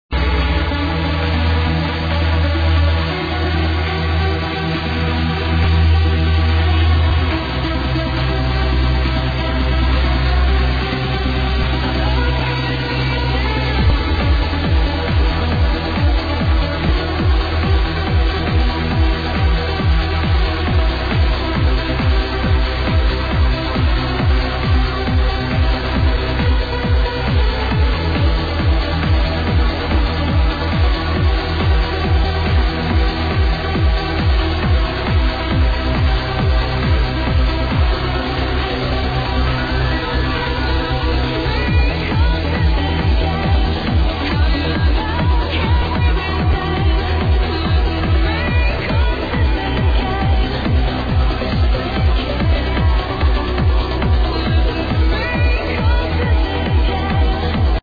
Please ID, uplifting trance